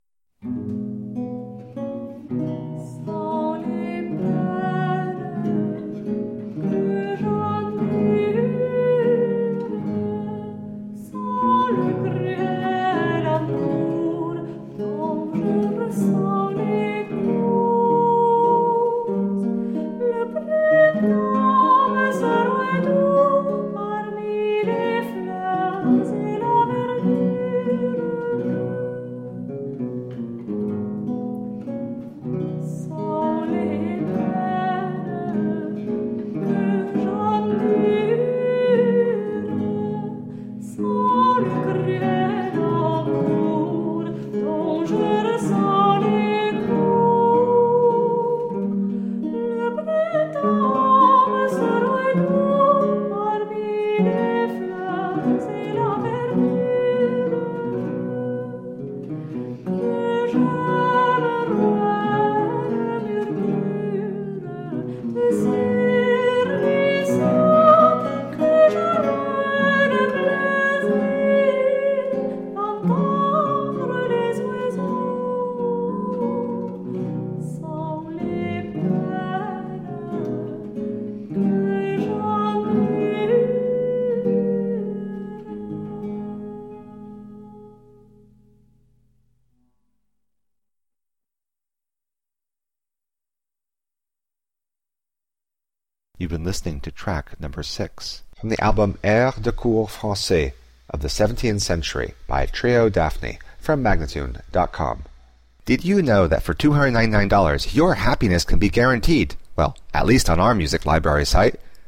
Delicate 17th century chansons.
the three women
with all-original instrumentation
with the intimate feeling of a parlor room recital.